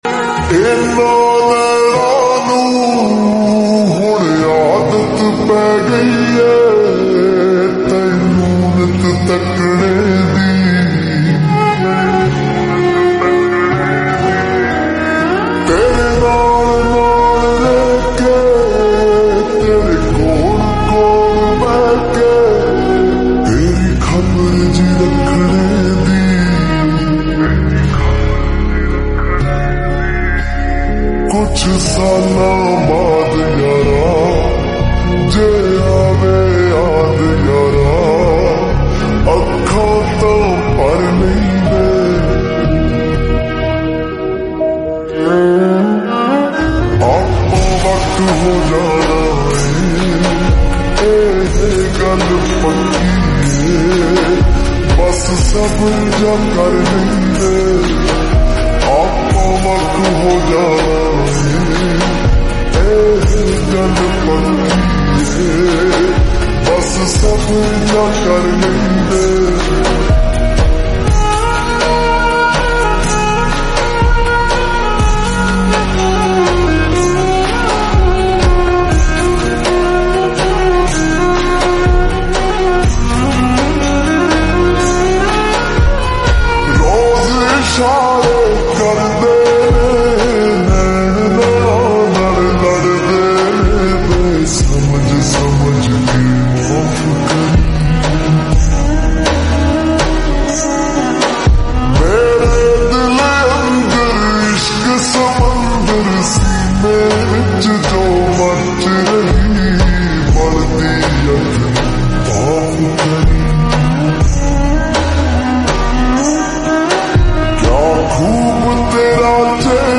SLOW REVERB SONG